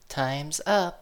snd_buzzer.ogg